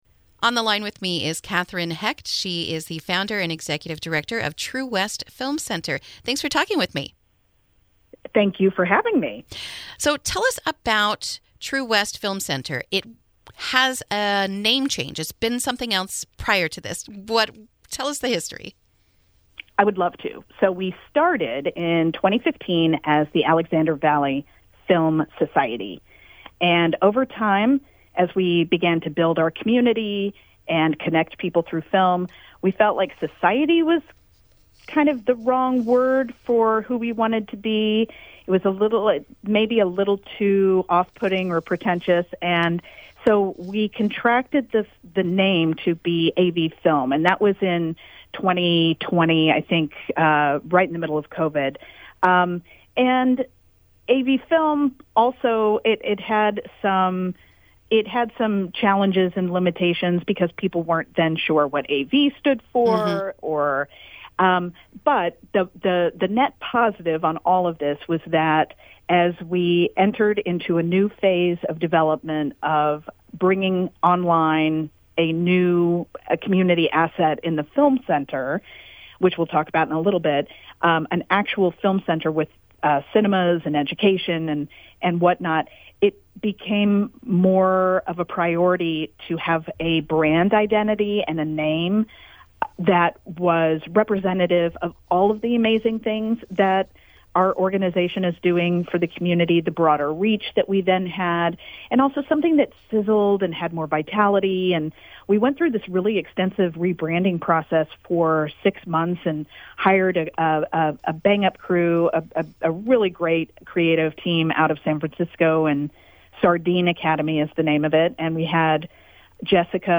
INTERVIEW: True West Film Center Groundbreaking Begins in Healdsburg